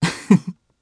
Fluss-Vox_Happy1_jp_b.wav